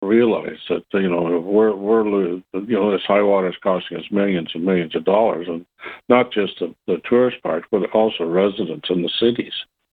As Quinte West Mayor Jim Harrison tells Quinte News, high water levels have hurt people and their property a lot.